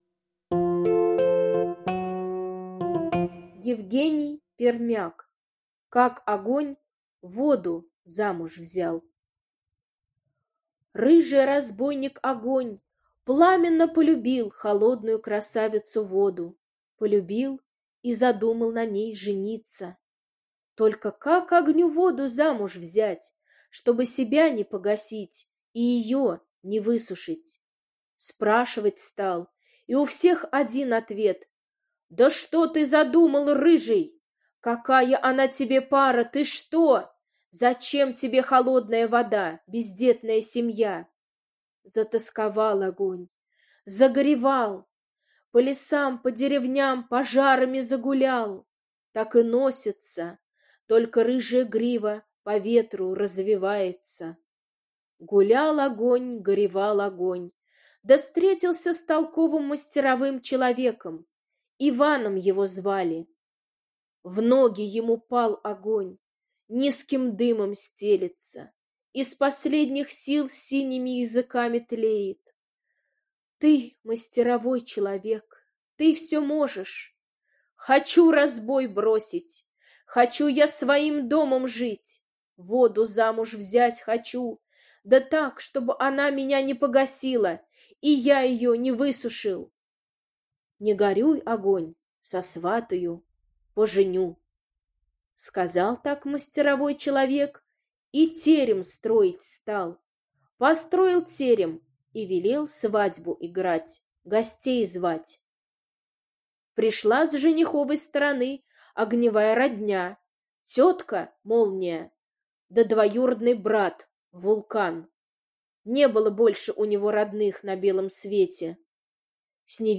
Как огонь воду замуж взял — аудиосказка Пермяка Е. Сказка о том, как Огонь пламенно полюбил холодную красавицу Воду и решил жениться на ней.